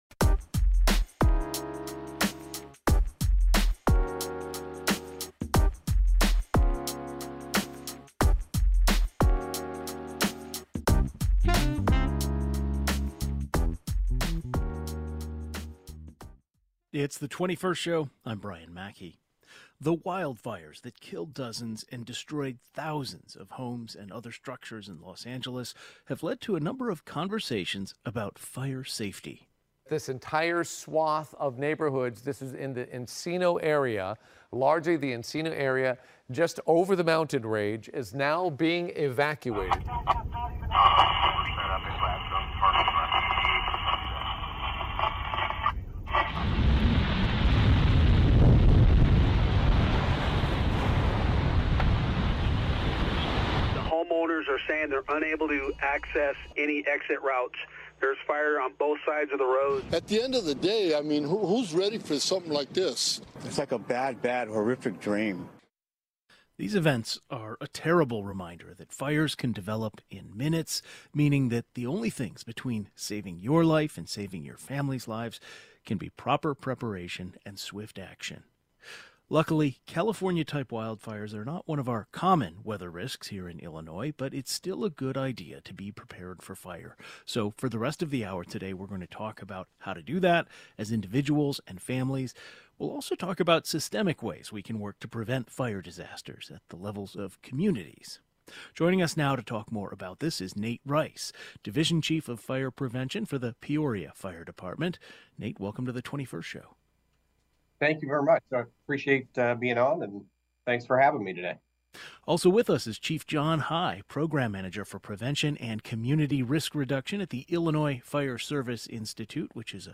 Fire safety experts discuss how to be prepared and systemic ways we can work to prevent fire disasters at the level of communities.